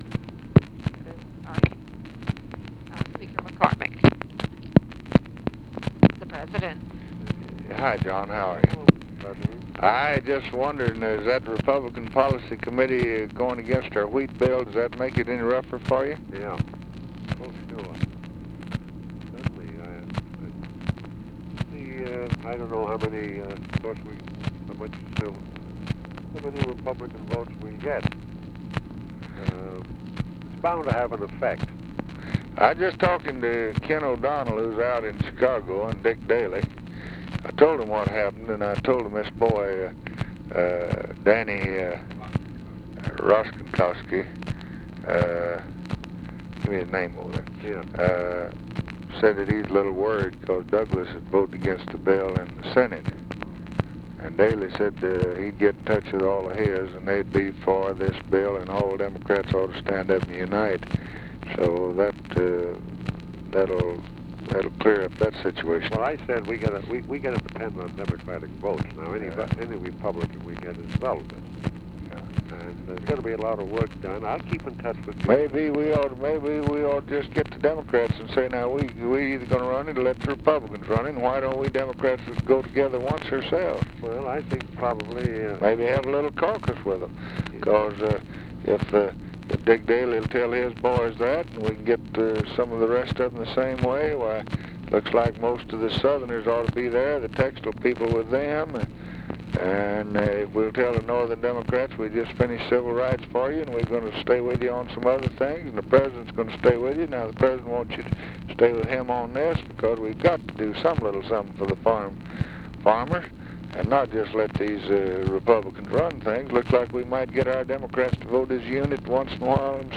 Conversation with JOHN MCCORMACK, March 11, 1964
Secret White House Tapes